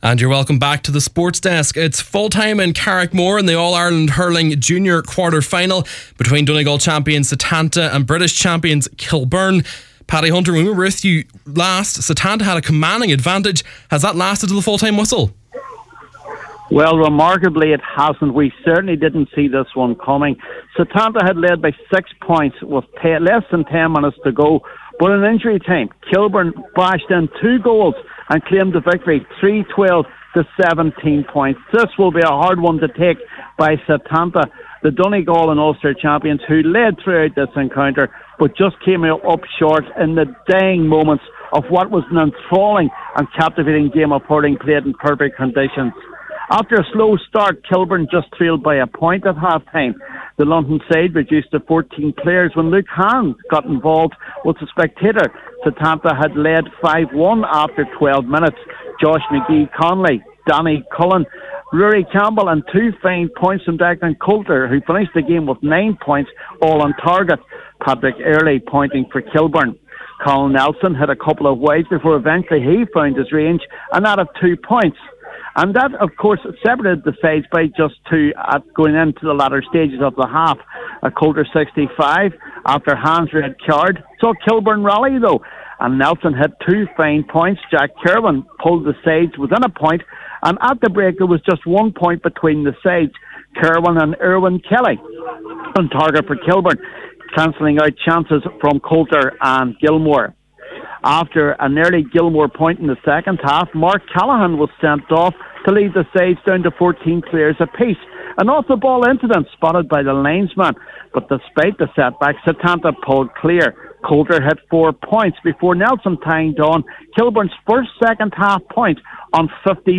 Setanta defeated in All-Ireland Junior Hurling Quarter-Final – FT Report